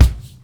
64FUNNY-BD-L.wav